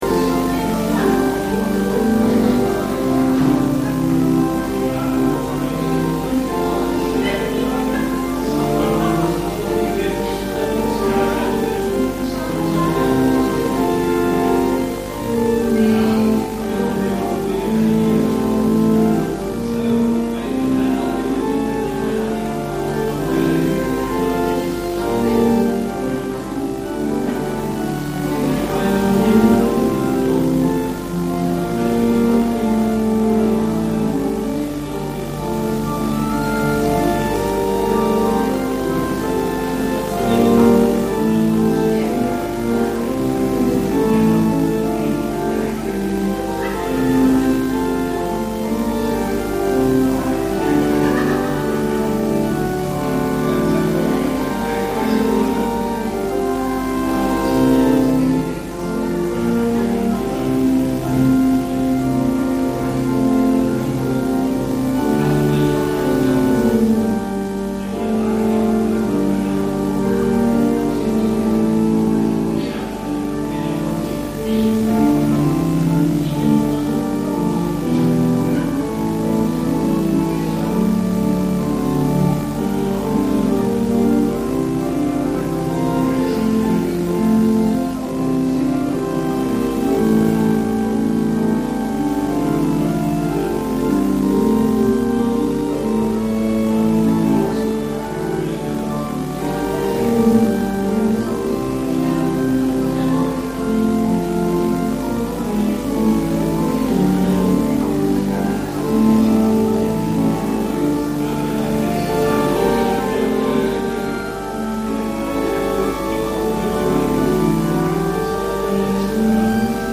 Dienst in Made